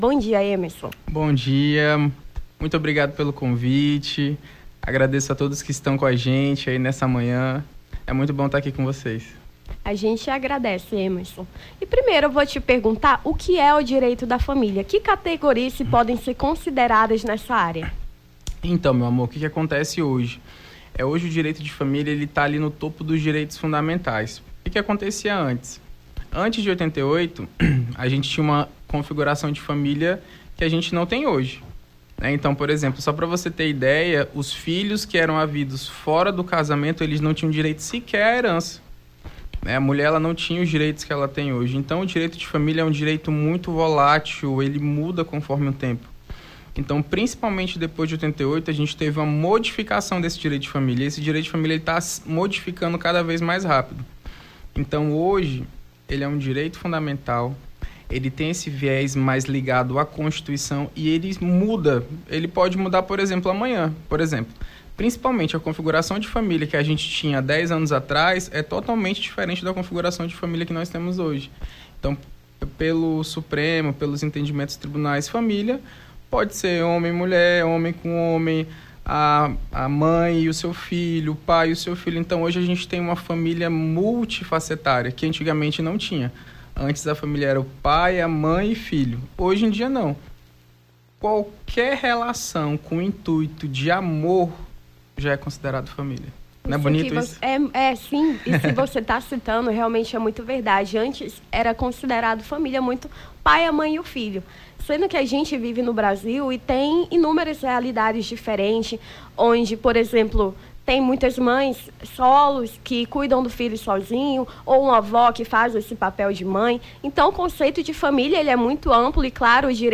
Nome do Artista - CENSURA - ENTREVISTA (DIREITOS DA FAMILIA) 14-07-23.mp3